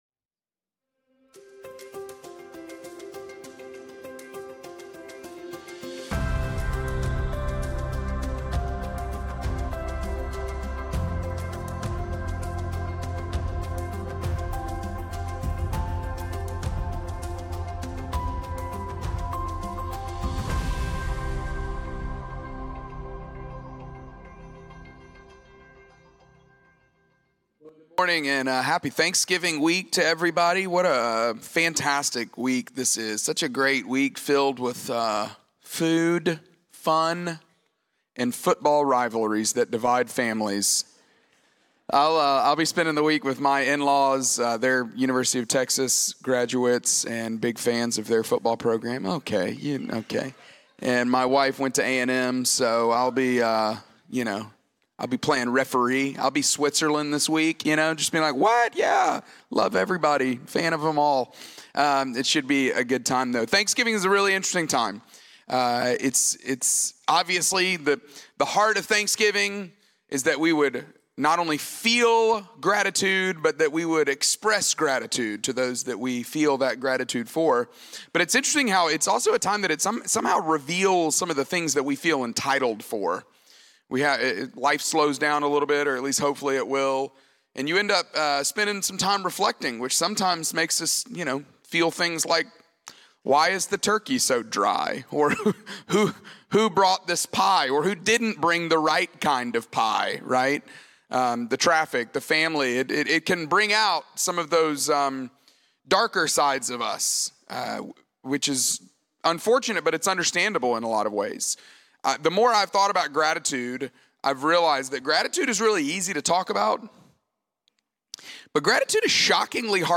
Faithbridge Sermons The Thanksgiving Paradox Nov 23 2025 | 00:33:09 1x 00:00 / 00:33:09 Subscribe Share Apple Podcasts Spotify Overcast RSS Feed Share Link Embed